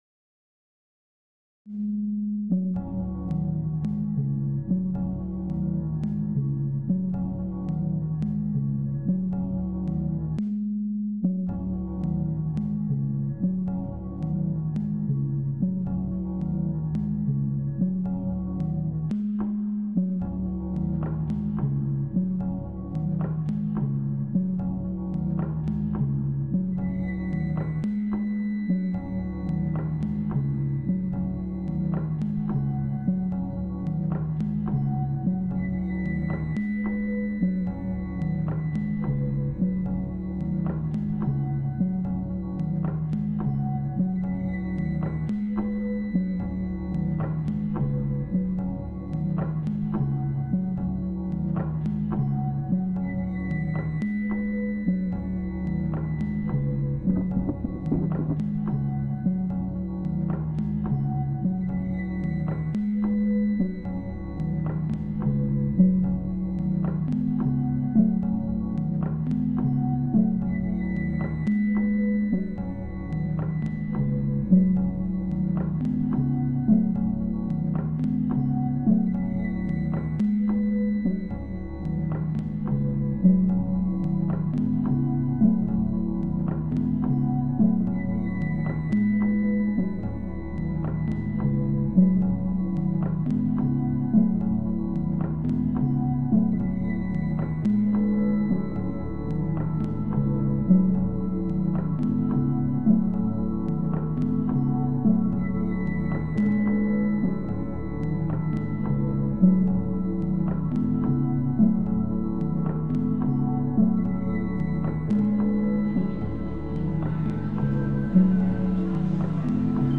I leapt on the Belfast St George's market recording as soon as I heard it, I've always loved the accent.